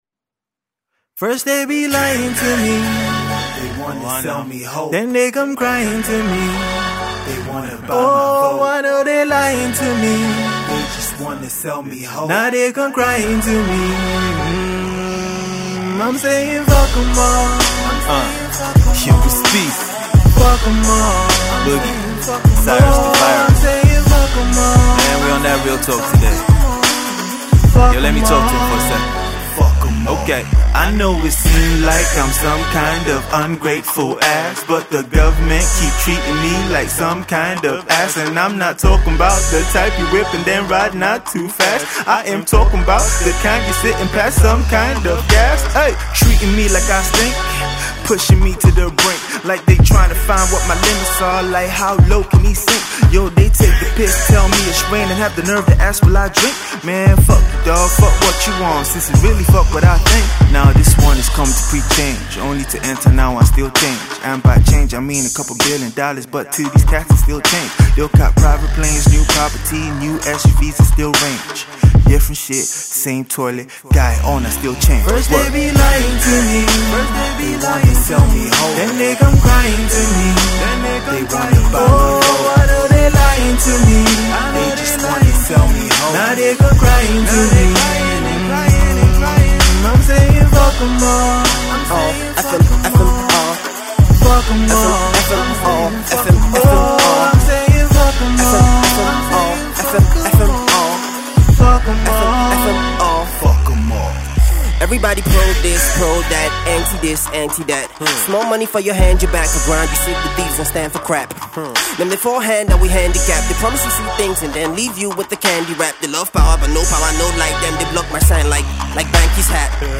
Another Election Song
Three different Nigerian MC’s